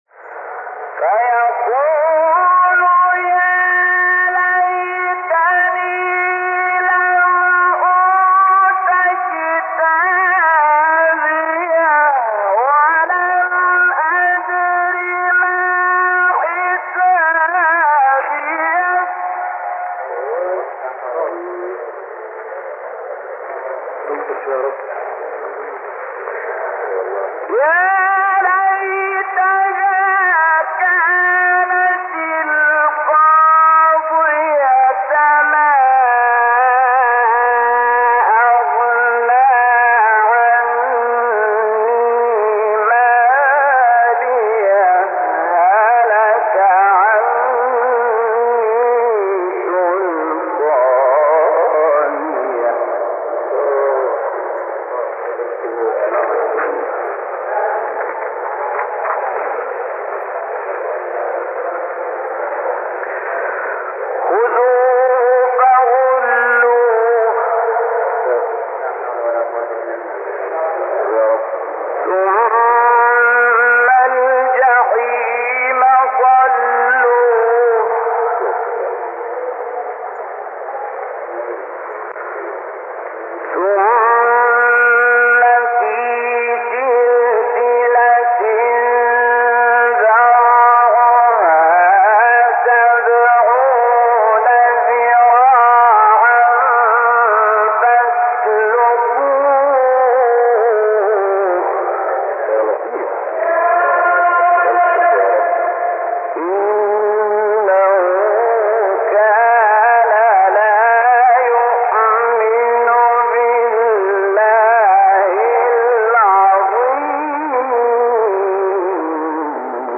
سوره : حاقه آیه : 25-52 استاد : محمد صدیق منشاوی مقام : مرکب خوانی(حجاز * بیات) قبلی بعدی